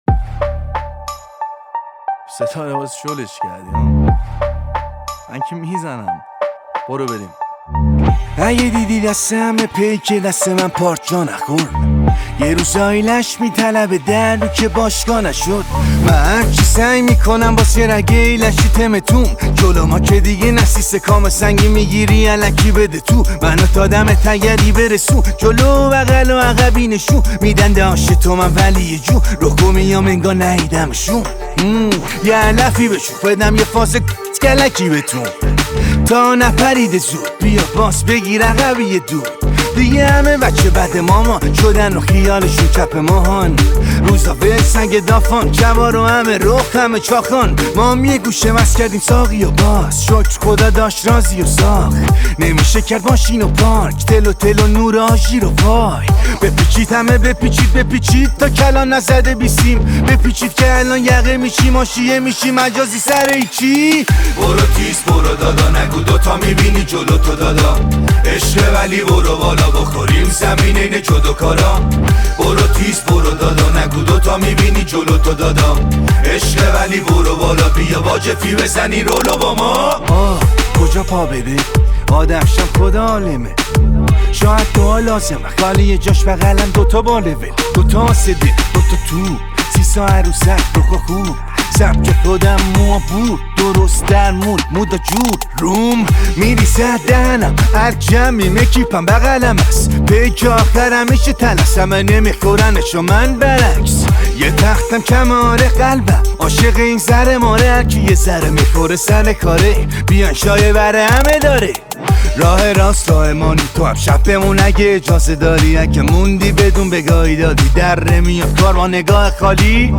خواننده سبک رپ